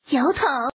Index of /client/common_mahjong_tianjin/mahjonghntj/update/1307/res/sfx/woman/